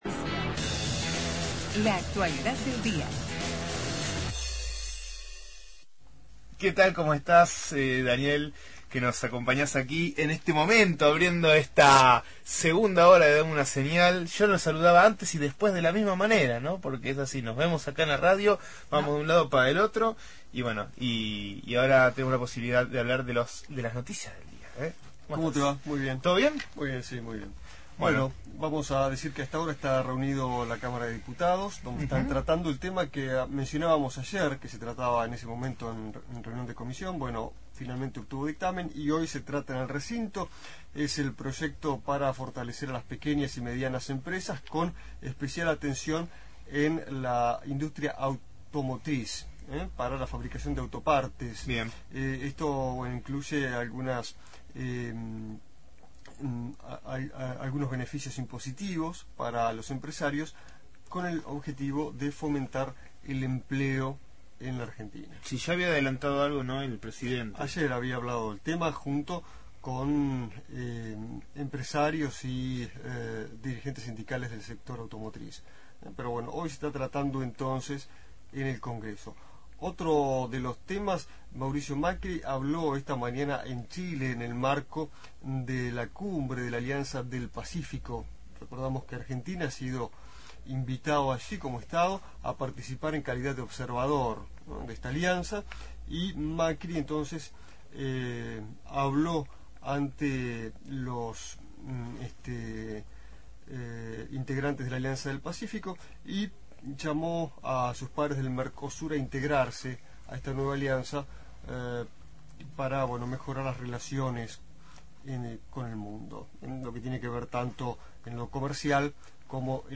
sintesis.mp3